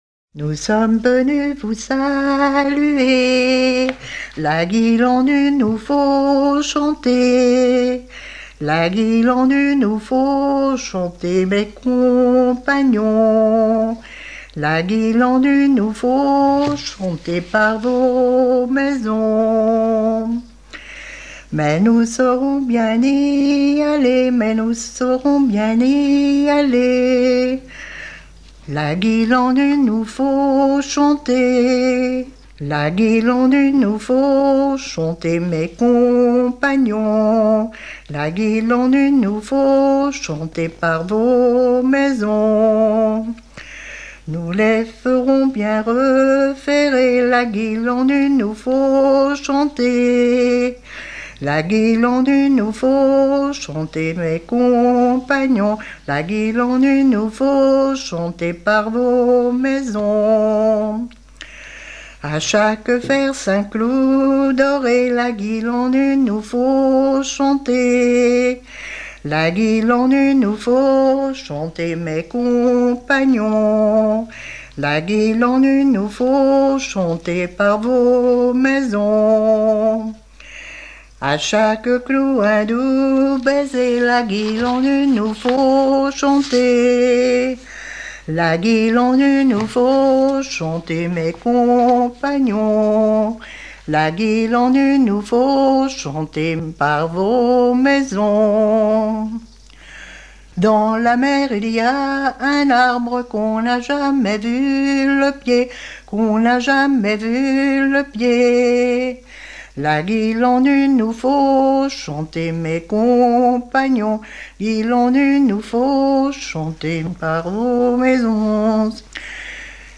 circonstance : quête calendaire
Genre strophique